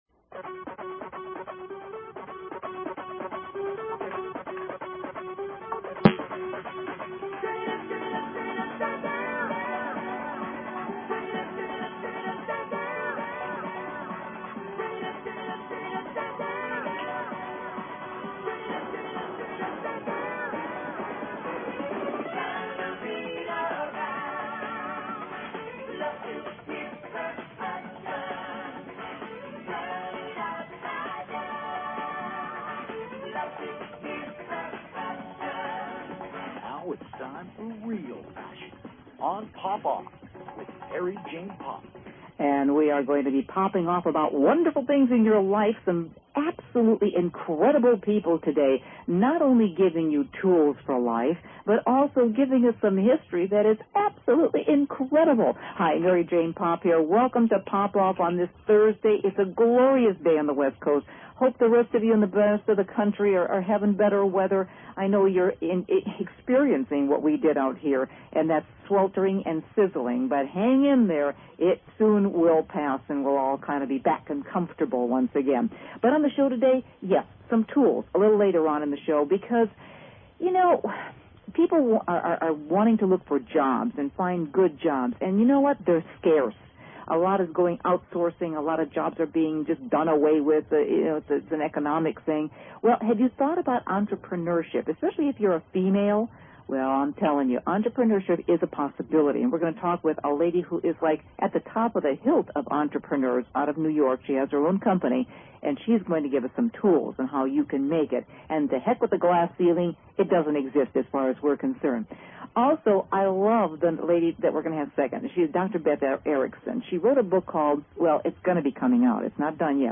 Talk Show Episode, Audio Podcast, Poppoff and Courtesy of BBS Radio on , show guests , about , categorized as
A fast-paced two hour Magazine-style Show dedicated to keeping you on the cutting edge of today's hot button issues.